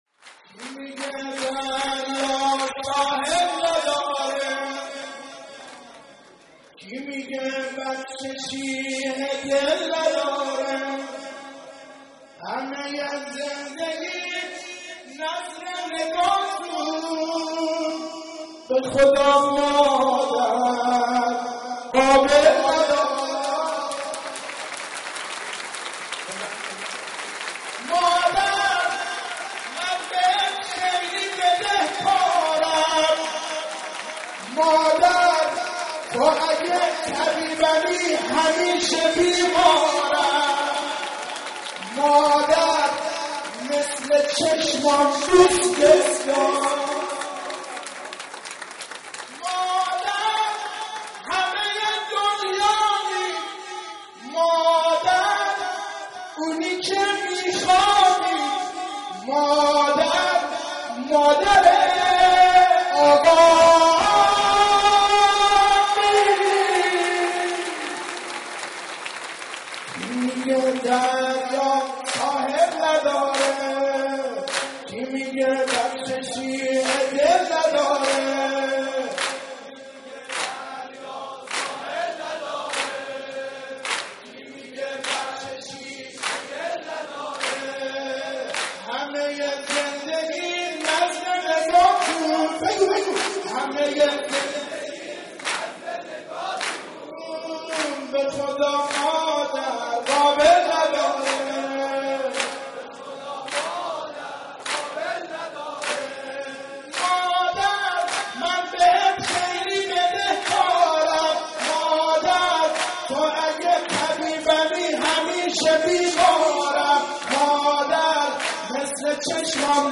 میلاد حضرت فاطمه(س) مهدیه امام حسن مجتبی(ع)